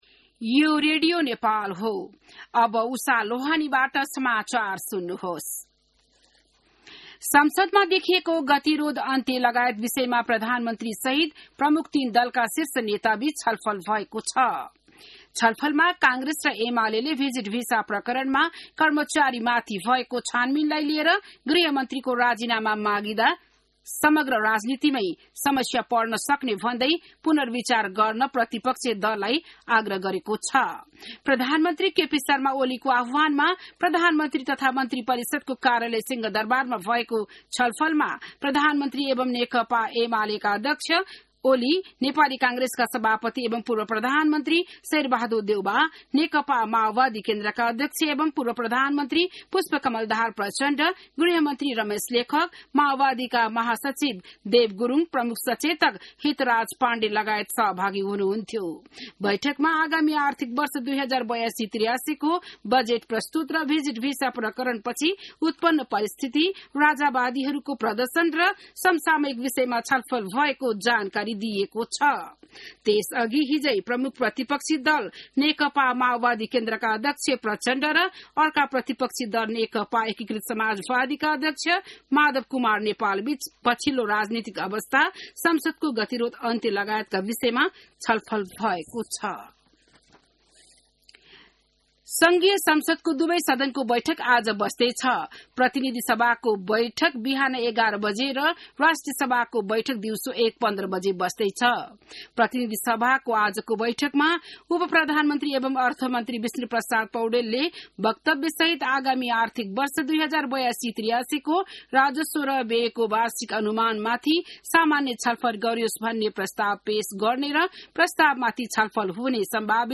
बिहान १० बजेको नेपाली समाचार : २० जेठ , २०८२